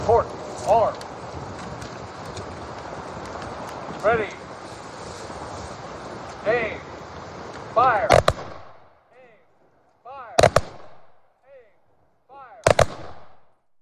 Solemnity shrouded the dedication of Emporia’s brand-new Latino Veterans Memorial on Saturday.
6069-memorial-salute.wav